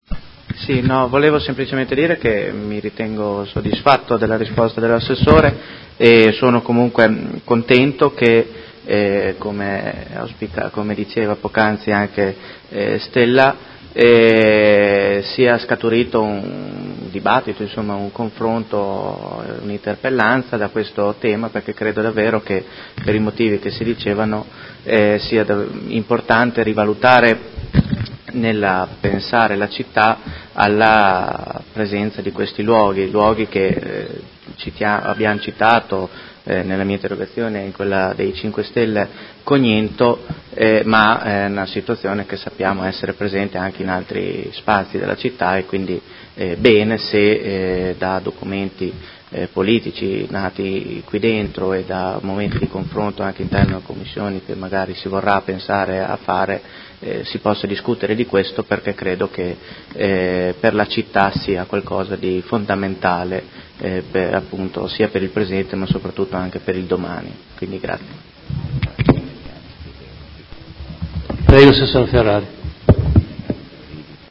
Seduta del 19/09/2019 Replica a risposta Assessora.